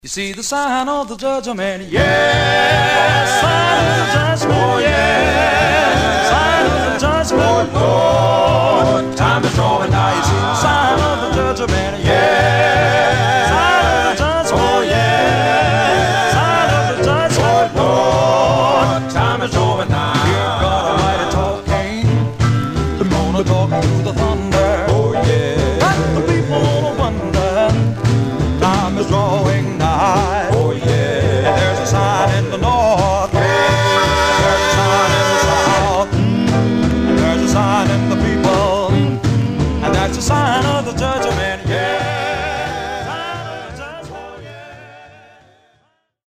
Condition Barely played Stereo/mono Stereo
Male Black Groups